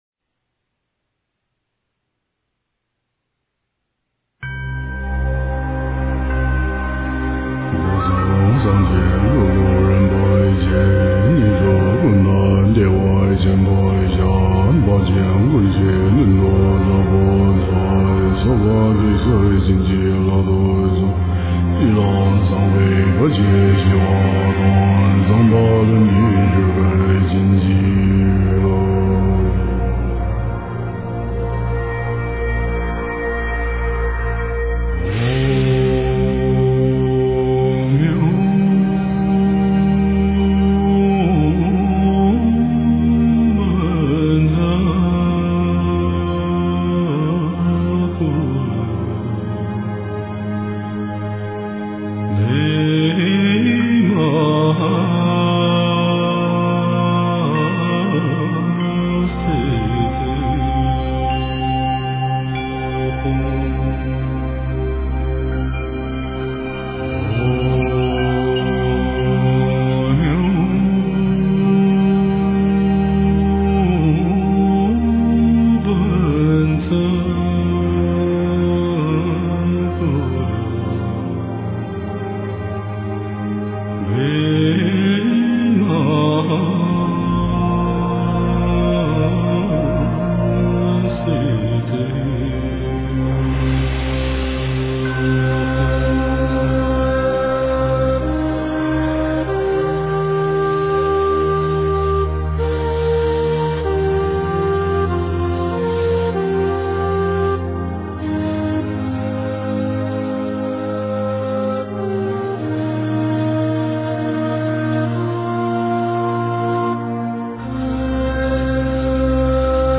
佛音 真言 佛教音乐 返回列表 上一篇： 楞严咒(快诵